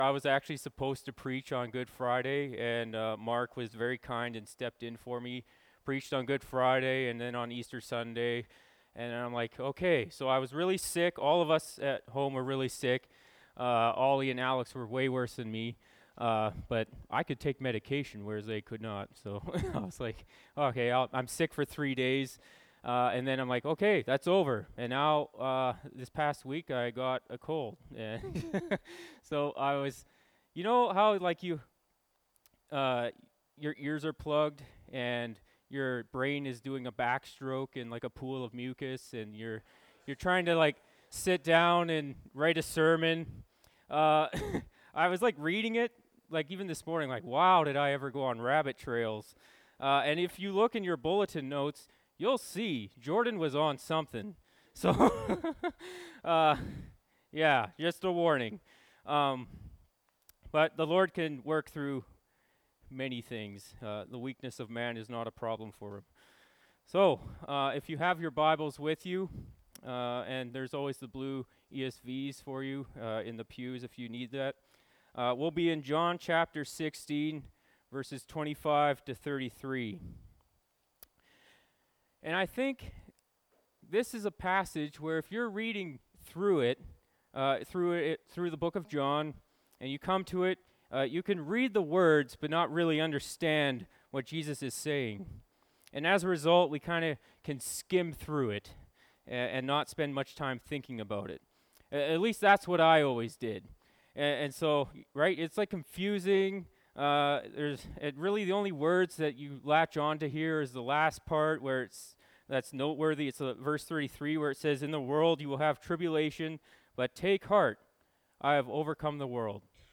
Apr 14, 2024 A World Overcome (John 16:25-33) MP3 SUBSCRIBE on iTunes(Podcast) Notes Discussion Sermons in this Series This sermon was preached and recorded in Grace Church -Salmon Arm and also preached in Grace Church - Enderby.